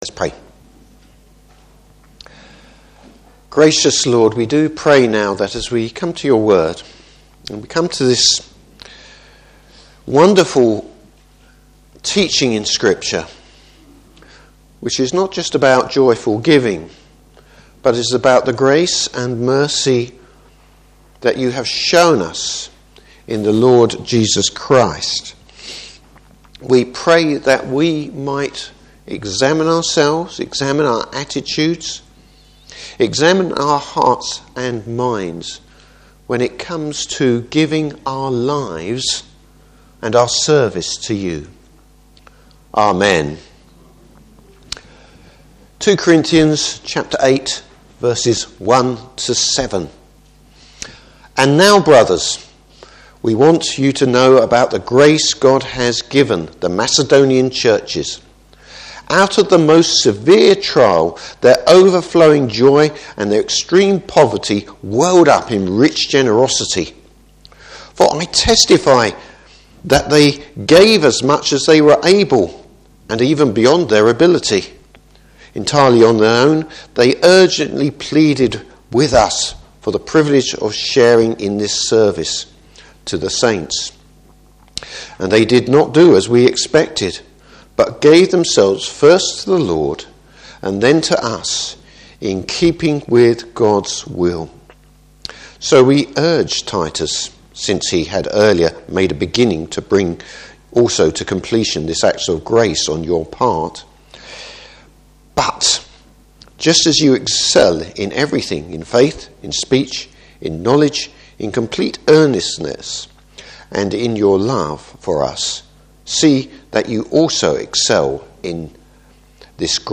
Service Type: Morning Service How should the Christian go about giving.